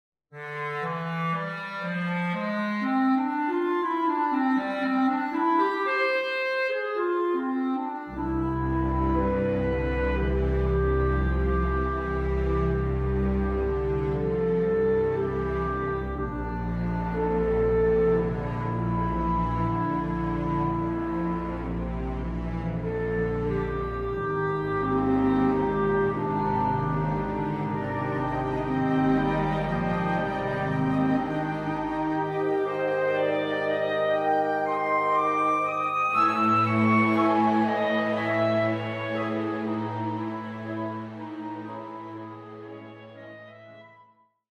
Clarinet in Bflat and Orchestra